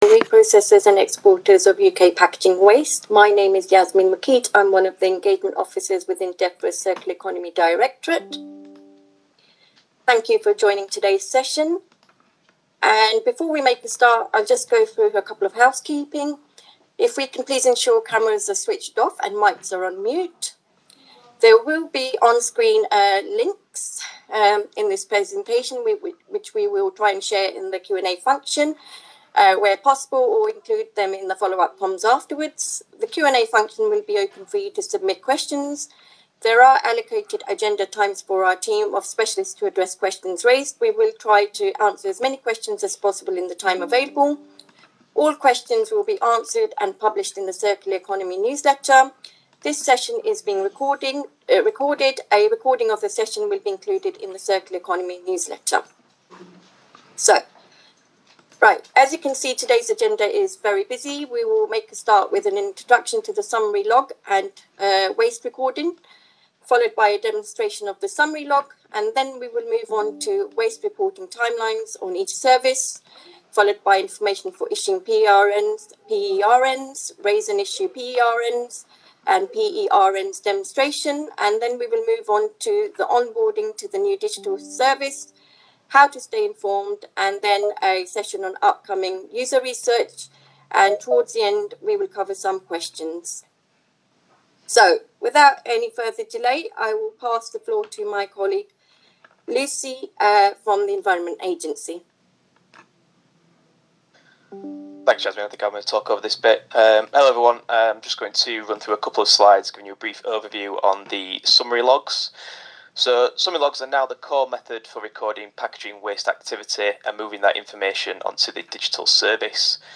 Reprocessors and exporters will have to record and submit all data and information relating to received and exported packaging on a new Summary Log. The system was described on a webinar on 11 November 2025.